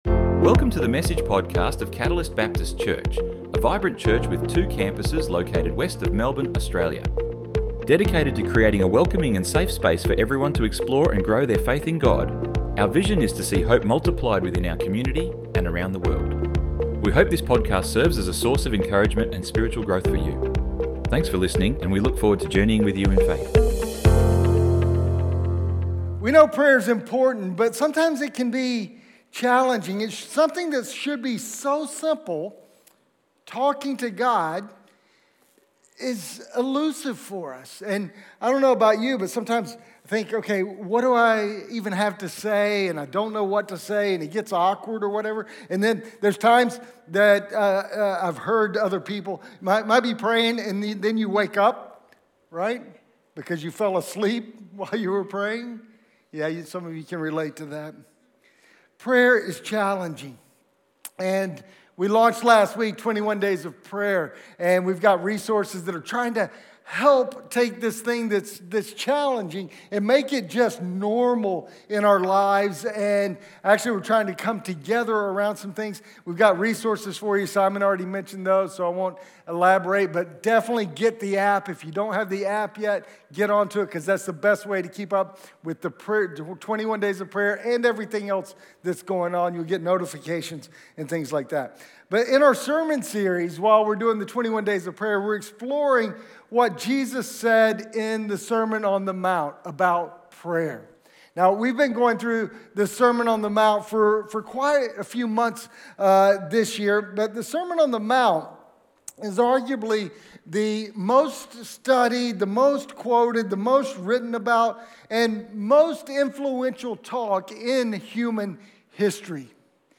Download Download Sermon Notes 02 - online notes - 21 Days of Prayer - Pray like this.doc Some of the most famous words Jesus ever spoke are found in what we call ‘The Lord’s Prayer’.